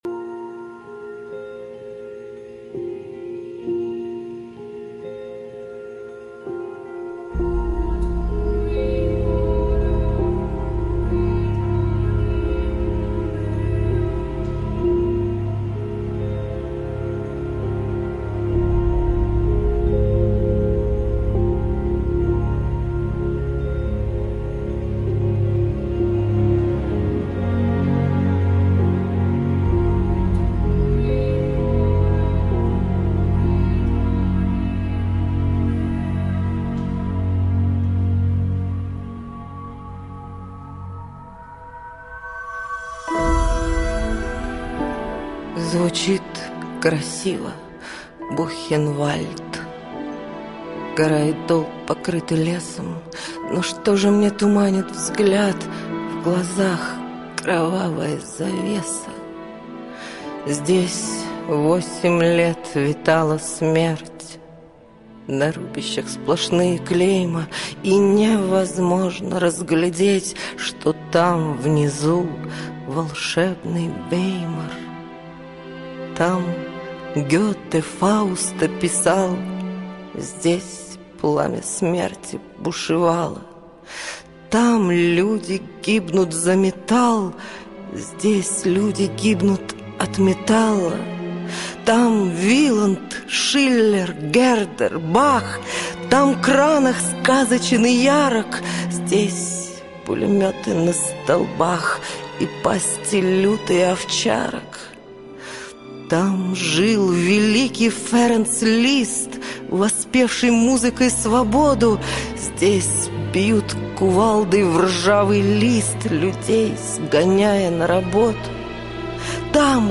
Ее декламация просто замечательная, ее голос проникает в души даже  не особенных любителей поэзии